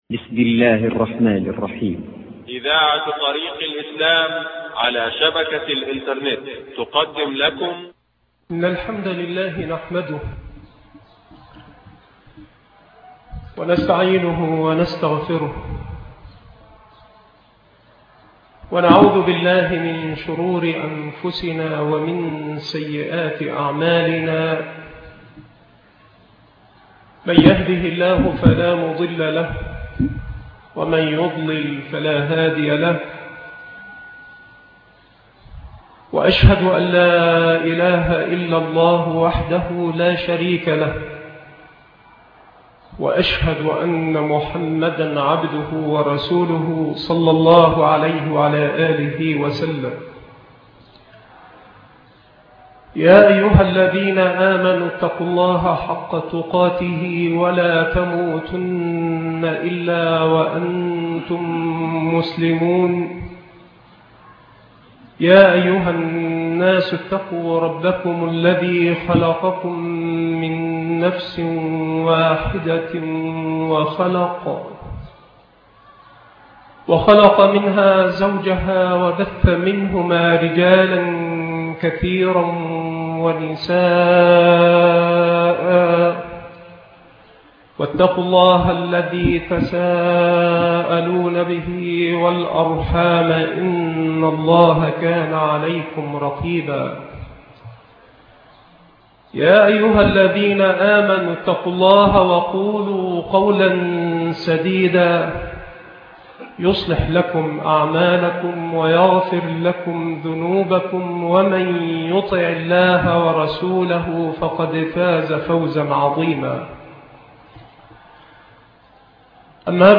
محاضرة